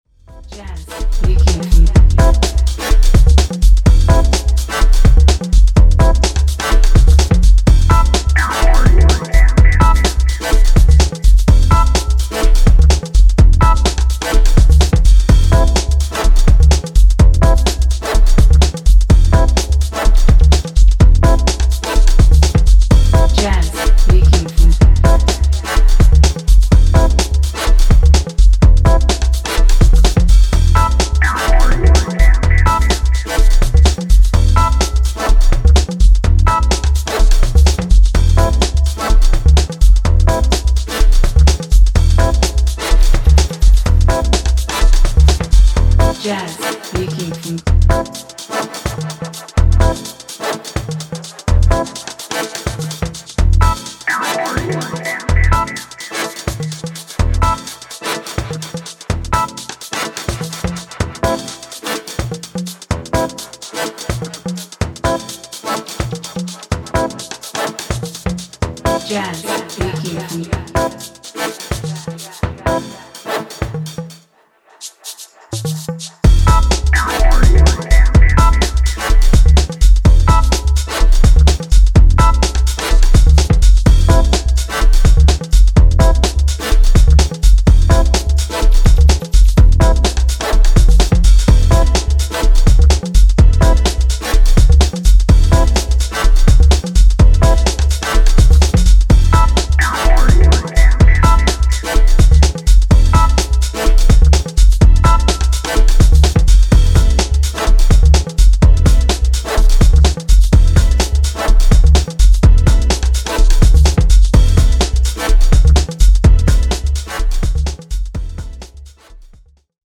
明滅するシンセに掛け合いが滑らかなブレイクビーツ・ハウス